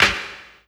Snares
Medicated Snare 16.wav